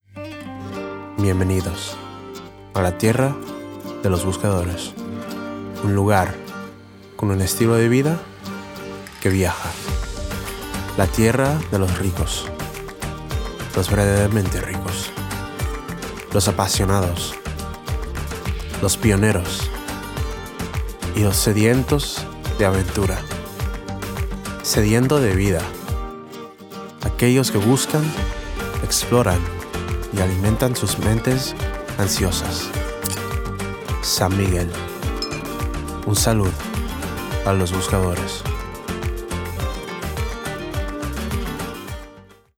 Commercial, Cool, Smooth, Confident